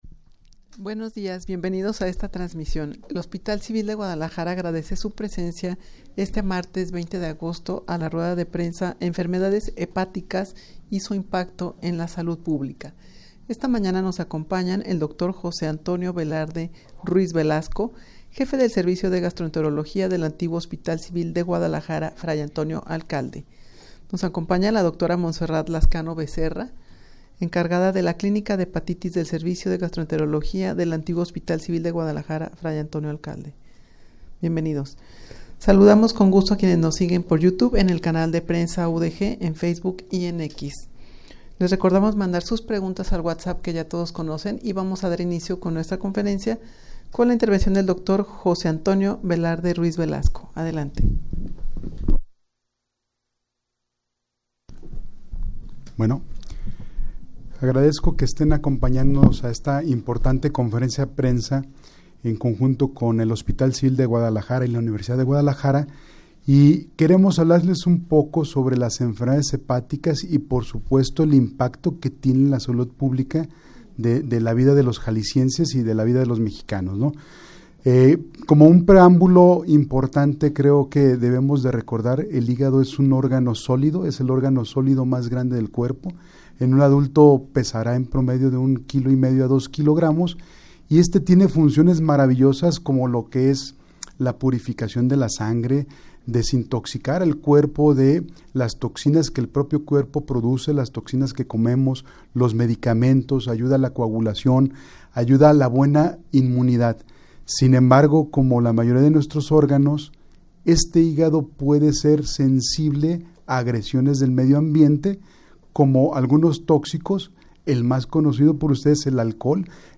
Audio de la Rueda de Prensa
rueda-de-prensa-enfermedades-hepaticas-y-su-impacto-en-la-salud-publica.mp3